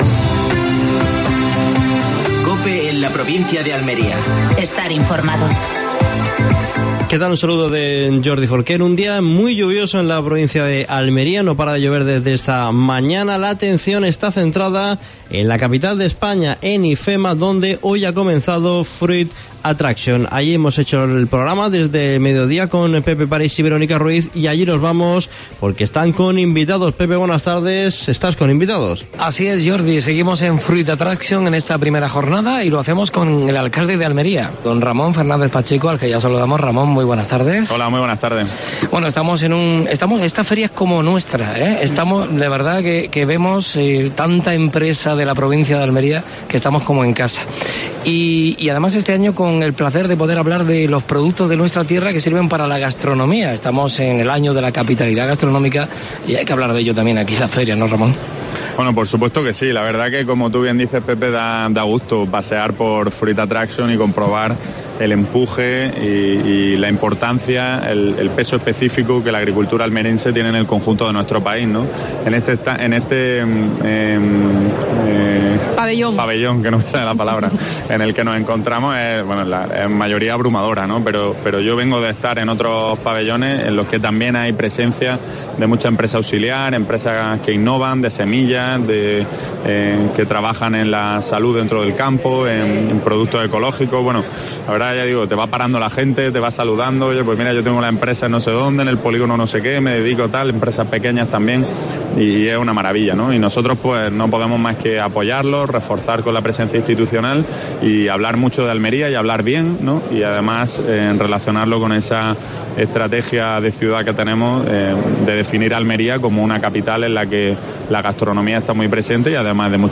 Actualidad en Almería. Entrevista a Ramón Fernández-Pacheco (alcalde de Almería) en la primera jornada de Fruit Attraction.